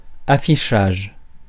Ääntäminen
Synonyymit affichement Ääntäminen France: IPA: [a.fi.ʃaʒ] Haettu sana löytyi näillä lähdekielillä: ranska Käännös Substantiivit 1. индикация Suku: m .